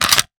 weapon_foley_pickup_14.wav